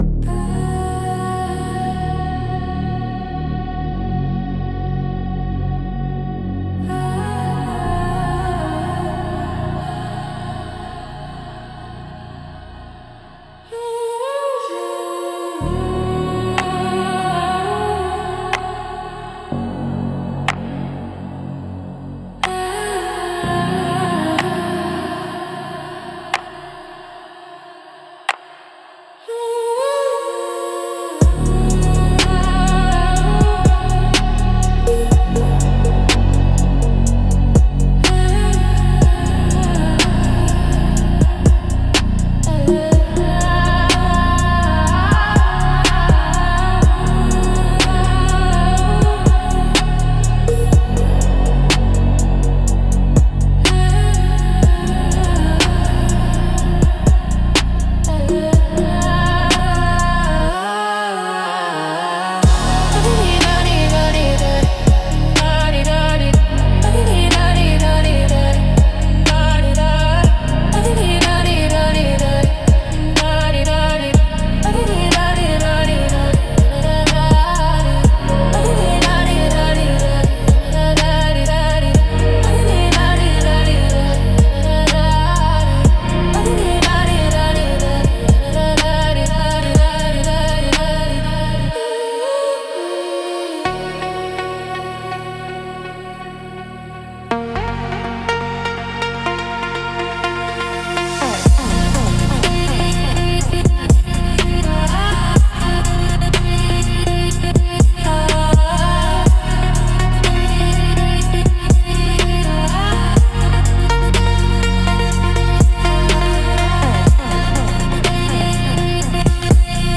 Instrumental - The Pulse Widens 3.54